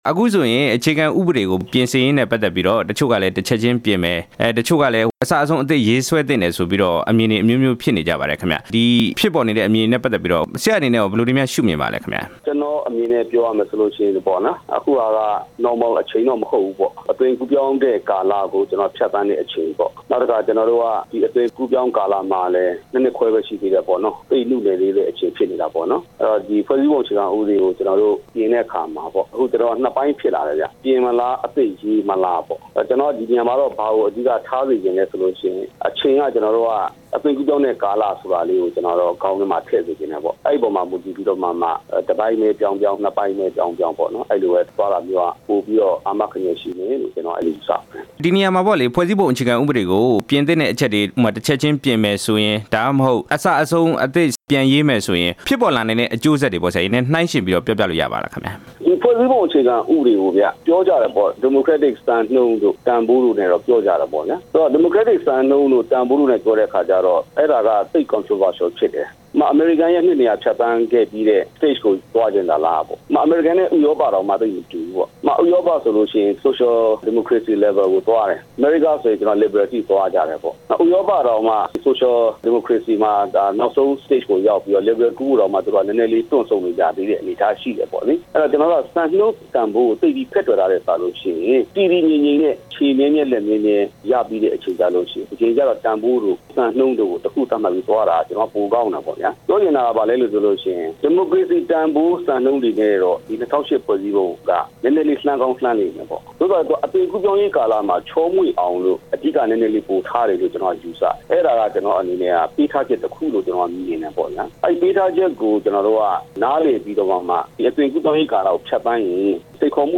အခြေခံဥပဒေပြုပြင်ရေး သမ္မတ နိုင်ငံရေးအကြံပေးနဲ့ ဆက်သွယ်မေးမြန်းချက်